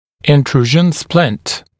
[ɪn’truːʒn splɪnt][ин’тру:жн сплинт]внедряющая шина, интрузионный сплинт